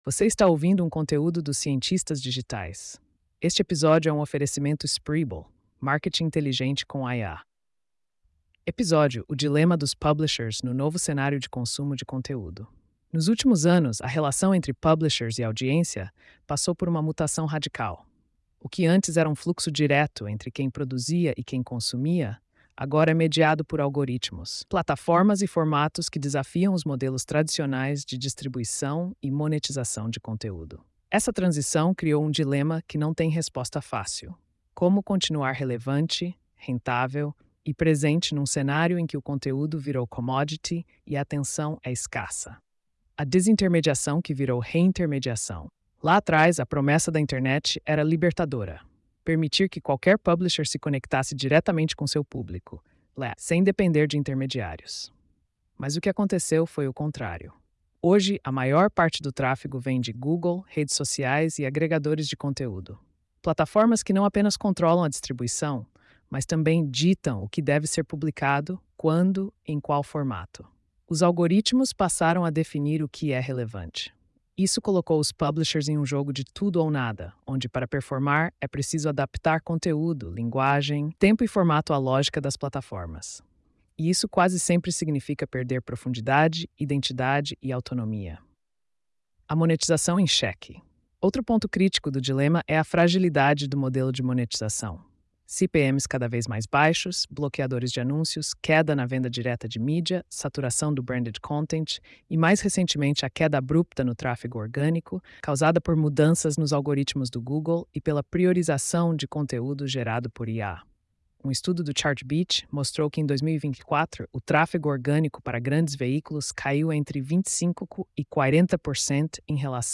post-3392-tts.mp3